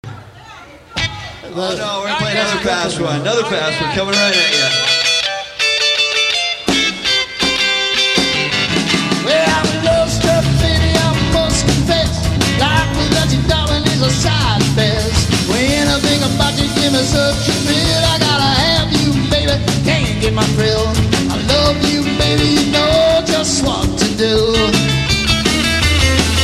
I have a deep love of the Blues that flavors everything I play.